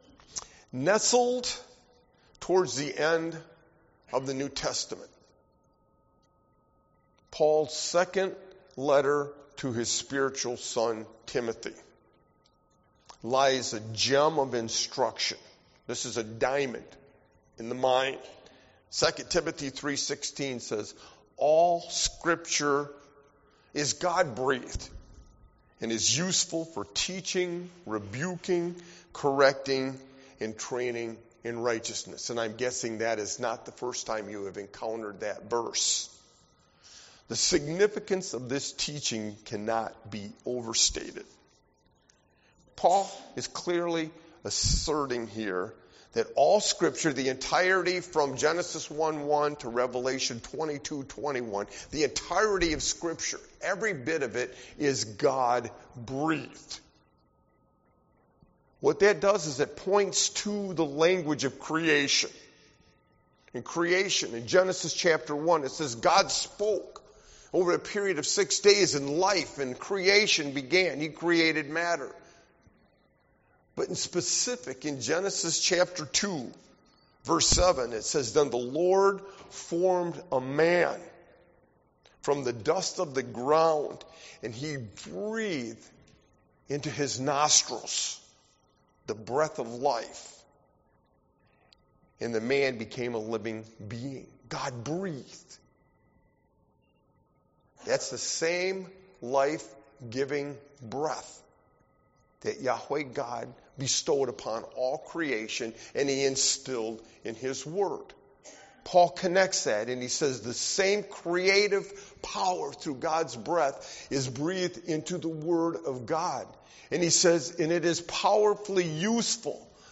Sermon-Insights-on-the-Triumphal-Entry-41022.mp3